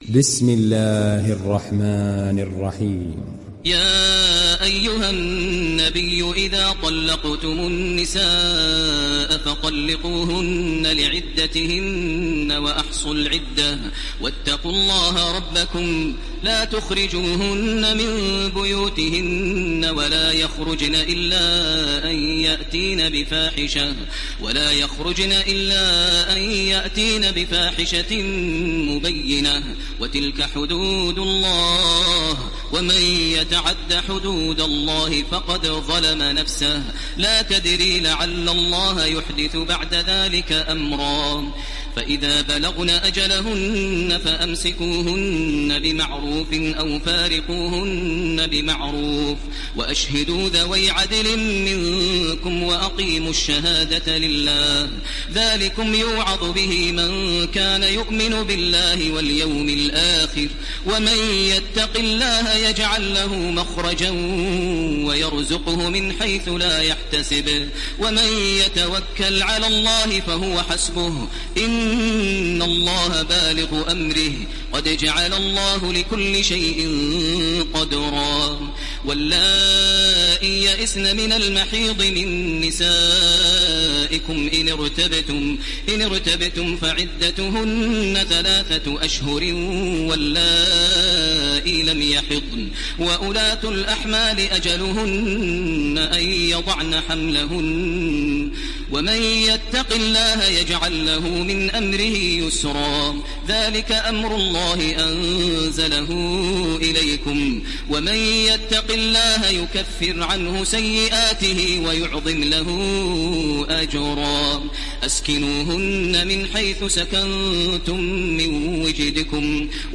ডাউনলোড সূরা আত-ত্বালাক Taraweeh Makkah 1430